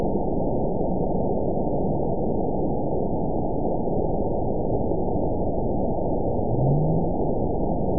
event 919880 date 01/27/24 time 17:30:28 GMT (1 year, 3 months ago) score 9.61 location TSS-AB01 detected by nrw target species NRW annotations +NRW Spectrogram: Frequency (kHz) vs. Time (s) audio not available .wav